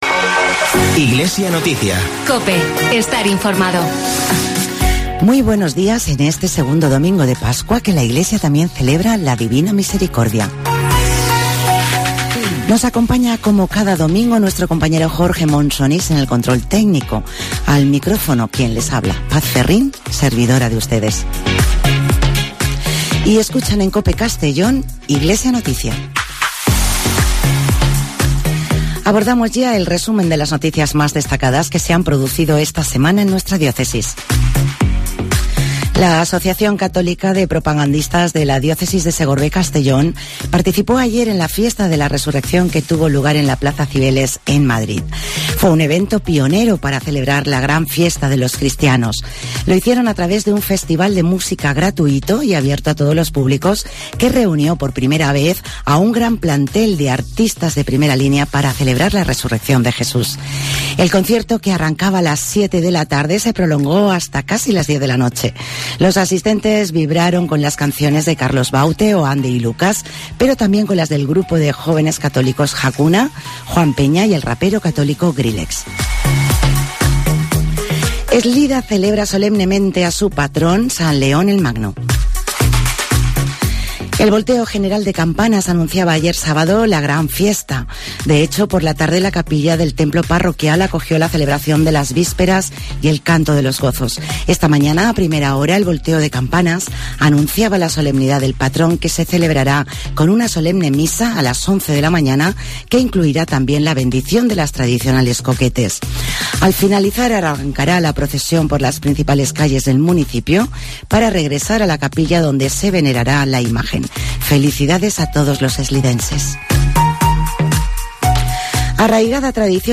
Espacio informativo de la Diócesis de Segorbe-Castellón